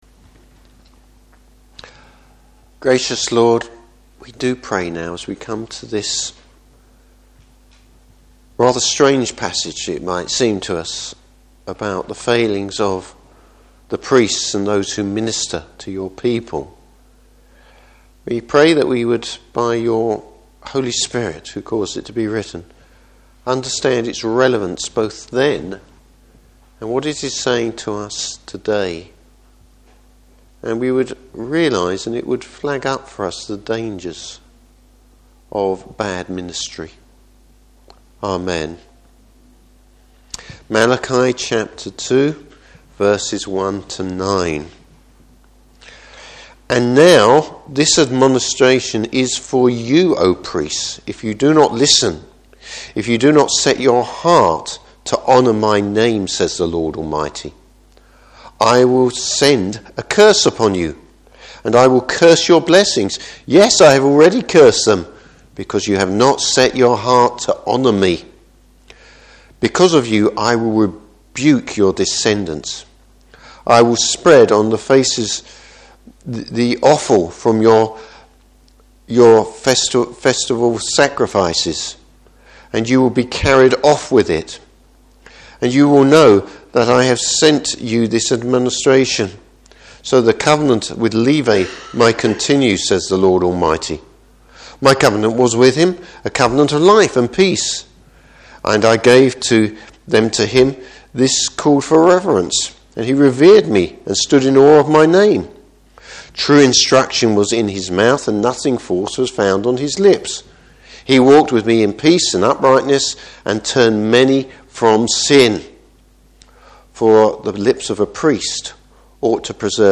Service Type: Morning Service The Lord will show up the Priesthood for what they are.